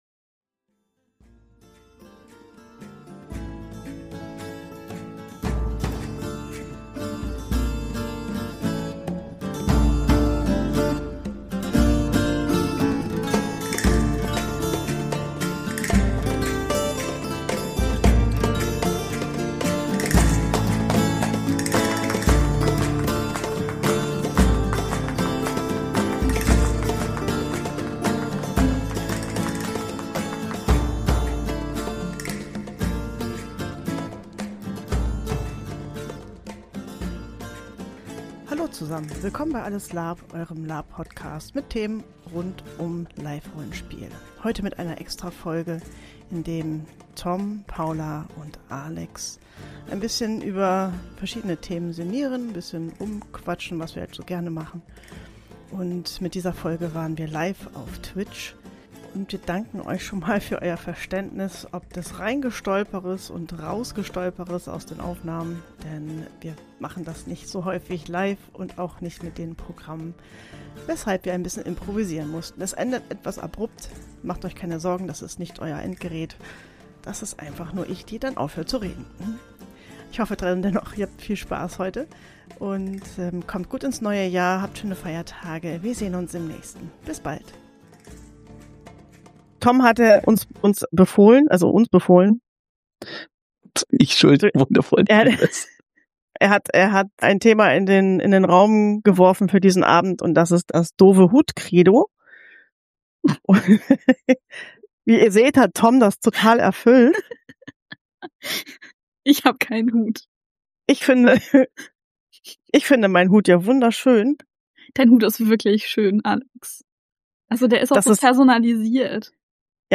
Folge 76 - Alles Larp? Livefolge 2025 ~ Alles Larp? Podcast